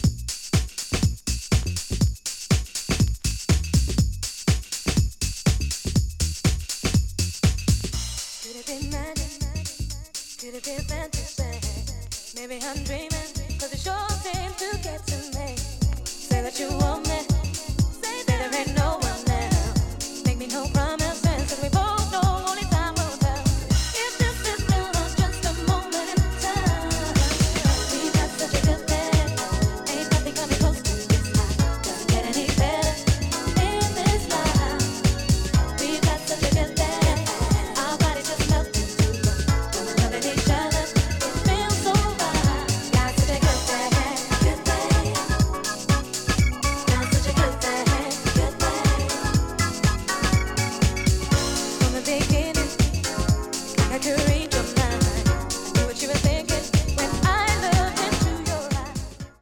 Vocal Club Mix
Dub Mix